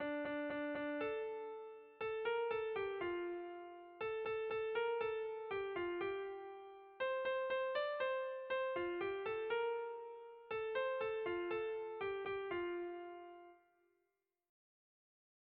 Erlijiozkoa
A-B